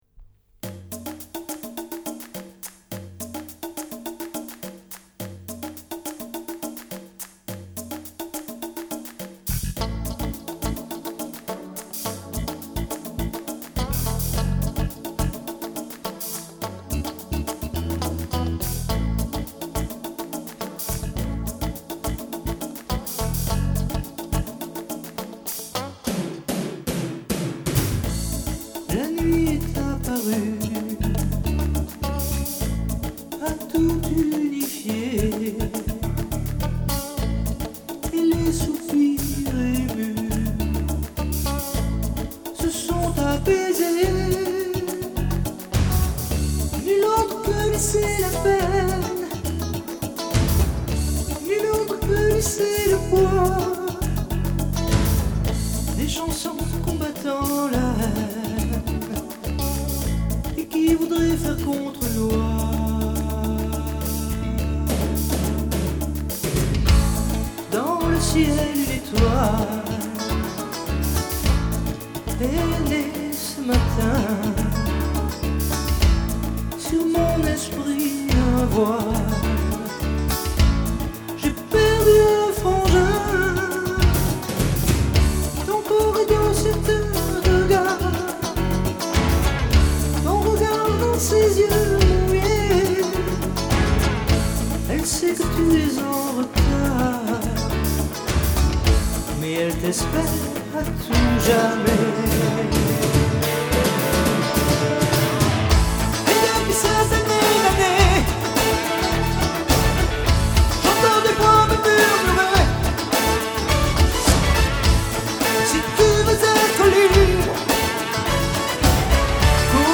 15 extraits de ses chansons en public au Lavoir Moderne Parisien
49 - 65 ans - Baryton